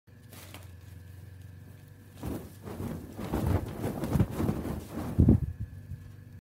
Tiếng Giũ dọn giường, chiếu, chăn ga gối đệm…
Thể loại: Tiếng động
Description: Tiếng giũ, lắc, đập, vỗ, phủi… tiếng tay kéo căng ga, gấp gọn chăn, xếp ngay ngắn gối và chấn chỉnh từng nếp đệm. Âm “phạch… phạch”, “bốp… bốp” dội nhẹ trong căn phòng, hòa cùng tiếng vải sột soạt...
tieng-giu-don-giuong-chieu-chan-ga-goi-dem-www_tiengdong_com.mp3